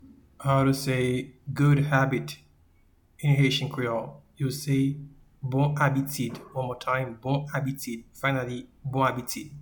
Pronunciation:
Good-habit-in-Haitian-Creole-Bon-abitid.mp3